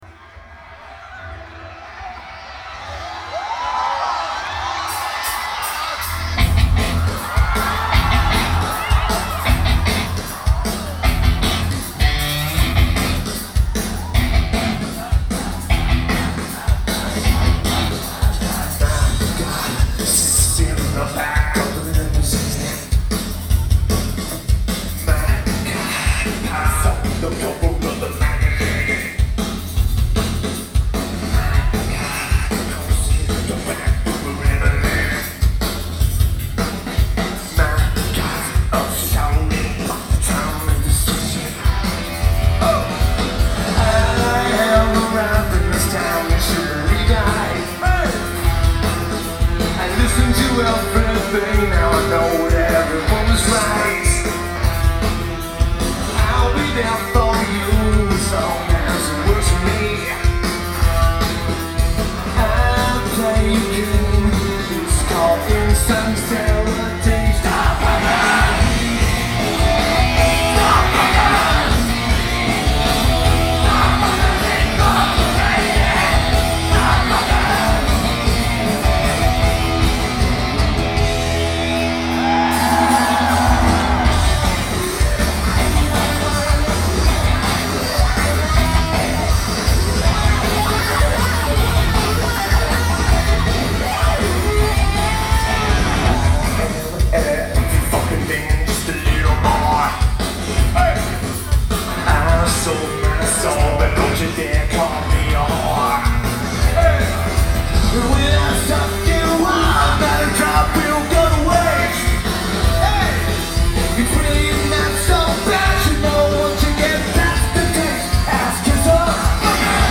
Studio Coast
Tokyo Japan
Lineage: Audio - AUD (DPA 4061 (HEB) + Korg MR-1)
Great recording.